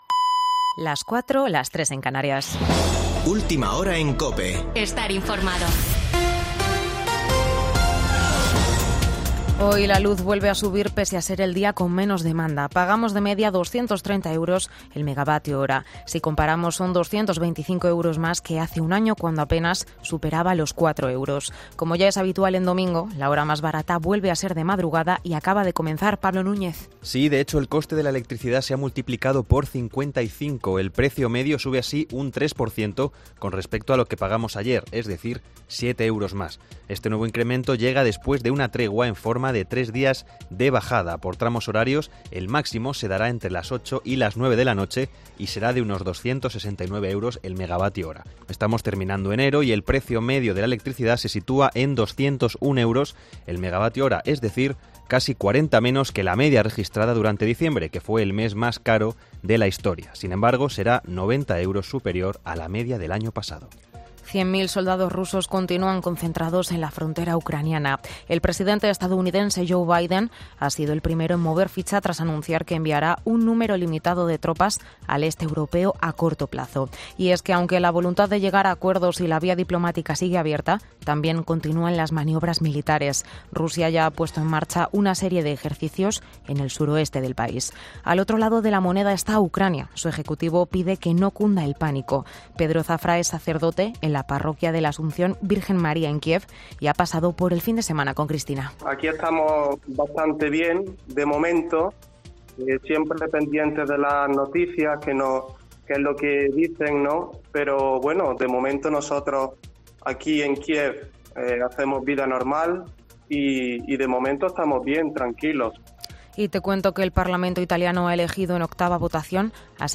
Boletín de noticias COPE del 30 de enero de 2022 a las 04.00 horas